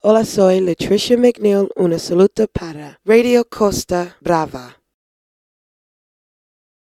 70f54da634d9e2b7e6c373fb42d7c8741be4c1b0.mp3 Títol Ràdio Costa Brava Emissora Ràdio Costa Brava Cadena Ona Catalana Titularitat Privada local Descripció Salutació de la cantant nord-americana, Lutricia McNeal.